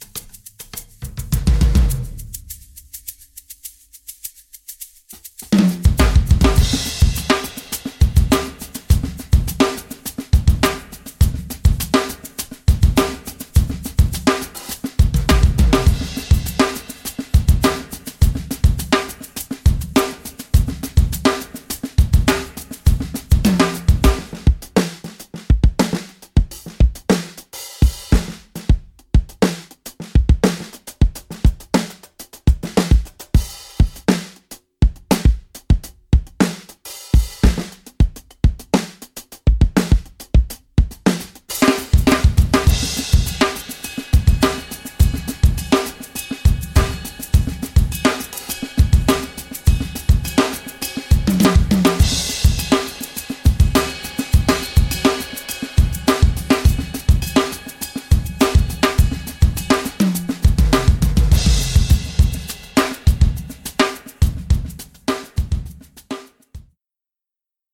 不仅适合那些希望访问 Benny 的声音进行制作或电子套件演奏的人，这个库还提供了一套非常有力和有特色的鼓音，具有多功能调音、两种不同的套件设置、整体泥土和温暖的声音，以及精确、亲密的氛围。
- 内置处理和混响。
- 我们最精心采样的鼓组合，包括五个小鼓，四个大鼓，四个军鼓和十个钹，都是 Sonor 鼓和 Meinl 钹。